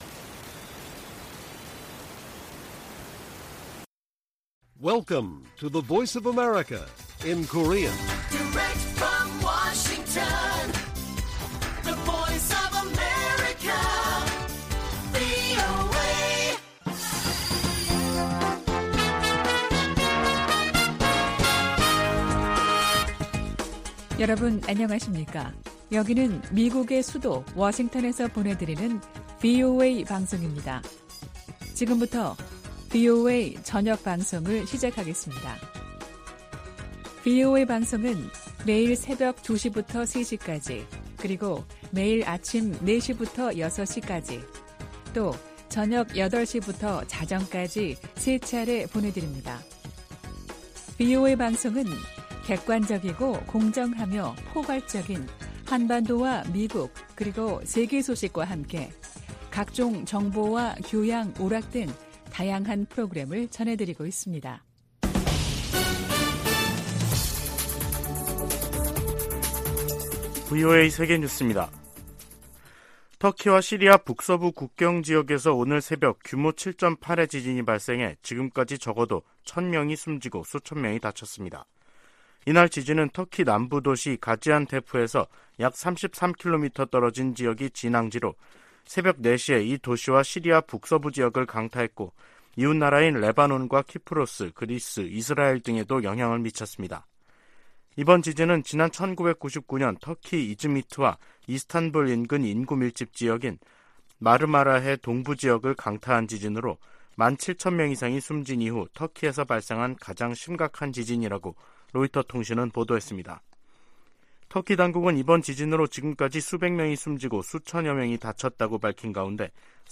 VOA 한국어 간판 뉴스 프로그램 '뉴스 투데이', 2023년 2월 6일 1부 방송입니다. 워싱턴에서 열린 미한 외교장관 회담에서 토니 블링컨 미 국무장관은, ‘미국은 모든 역량을 동원해 한국 방어에 전념하고 있다’고 말했습니다. 미국과 중국의 ‘정찰 풍선’ 문제로 대립 격화 가능성이 제기되고 있는 가운데, 북한 문제에 두 나라의 협력 모색이 힘들어질 것으로 전문가들이 내다보고 있습니다.